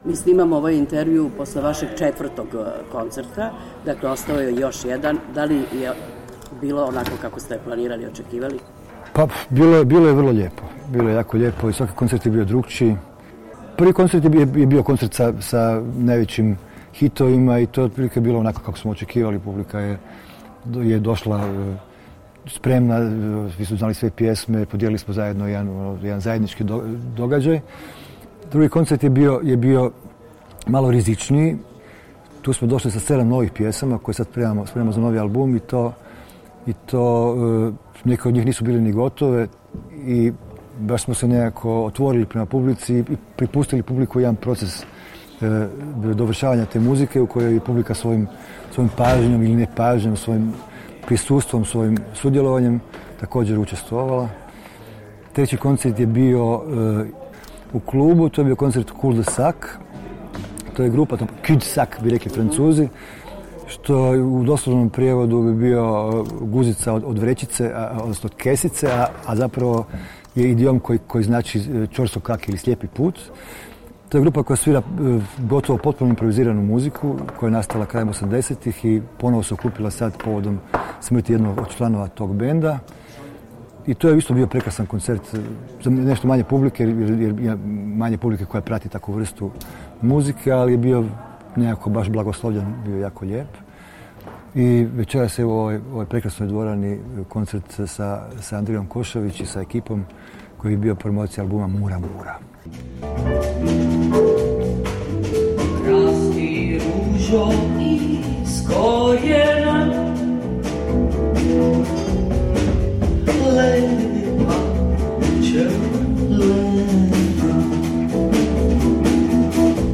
Intervju sa Darkom Rundekom